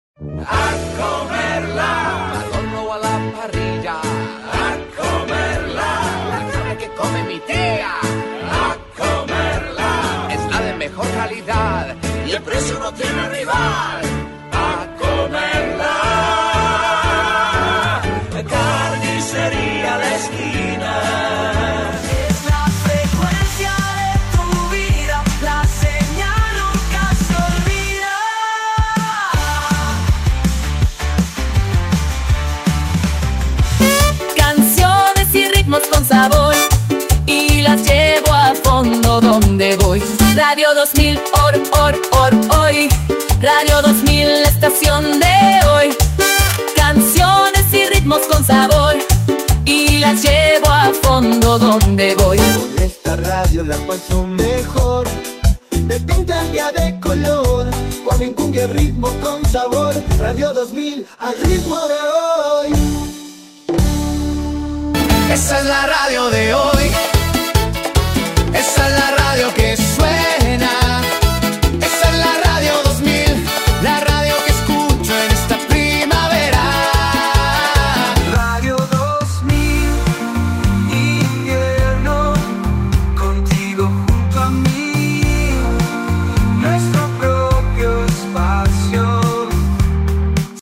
JINGLES CANTADOS COMERCIALES